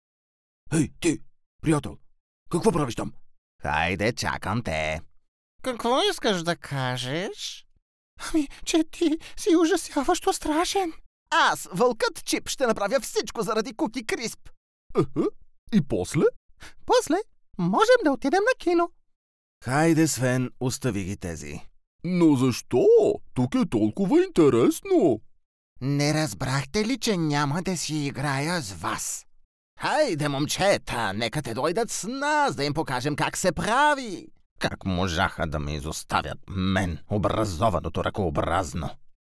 Commercieel, Natuurlijk, Stoer, Warm, Zakelijk